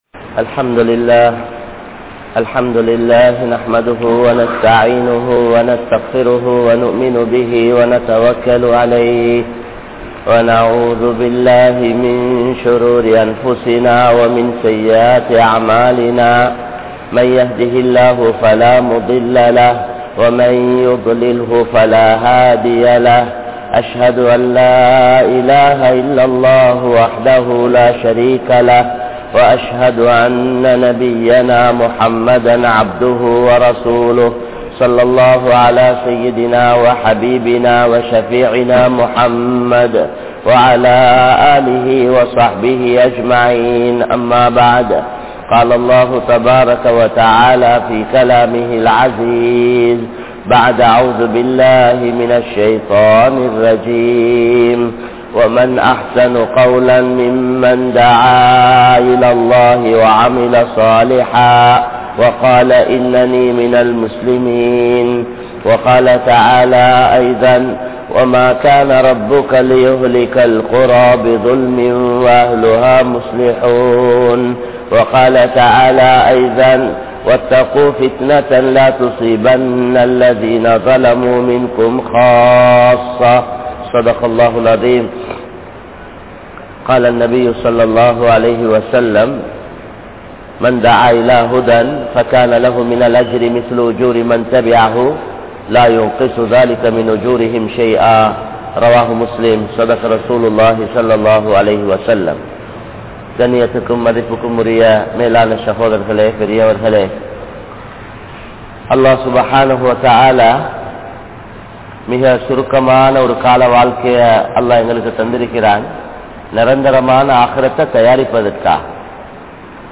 Dhauwath Ean Seiya Veandum?? (தஃவத் ஏன் செய்ய வேண்டும்??) | Audio Bayans | All Ceylon Muslim Youth Community | Addalaichenai
Kabeer Jumua Masjith